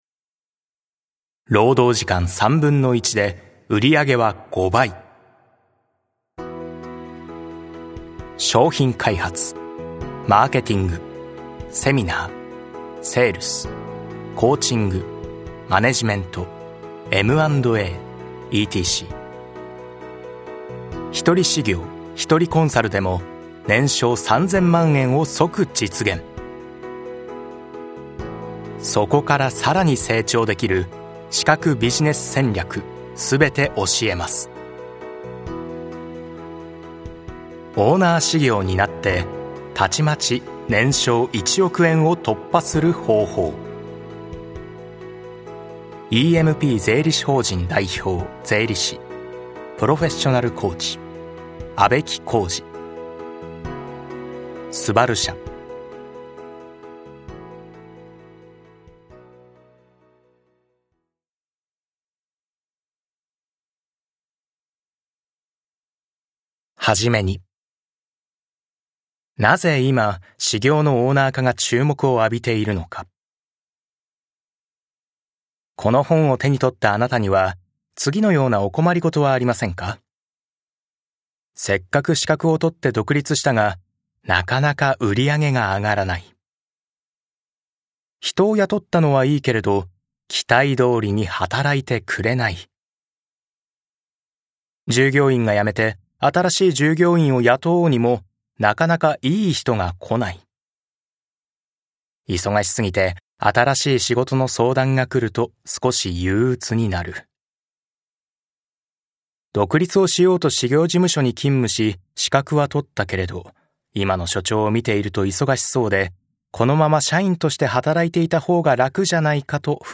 [オーディオブック] オーナー士業(R)になって、たちまち年商1億円を突破する方法